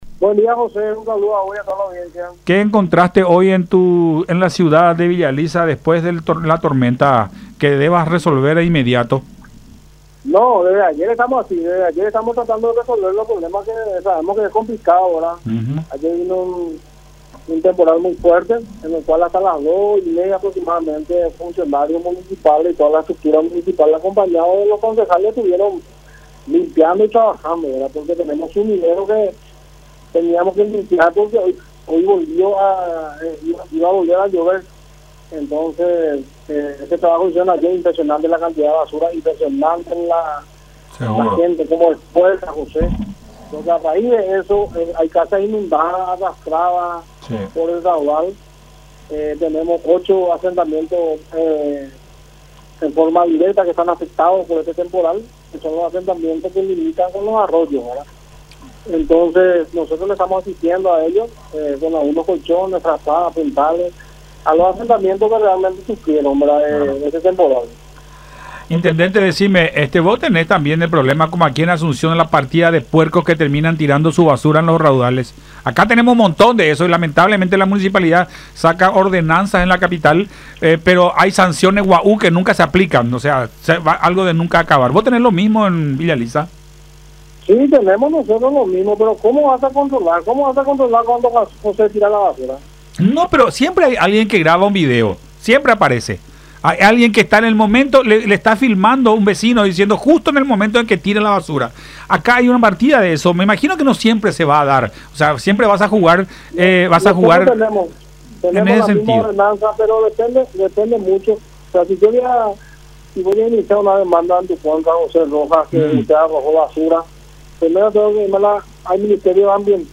“Hasta las 02:30 de hoy estuvieron trabajando los funcionarios municipales estuvieron trabajando en limpieza en los sumideros. Hay casas inundadas por los raudales. Tenemos ocho asentamientos afectados de forma directa por este temporal, los cuales limitan con arroyos. Les estamos asistiendo con colchones, frazadas y puntales”, dijo Ricardo Estigarribia, intendente de Villa Elisa, en diálogo con Nuestra Mañana por La Unión.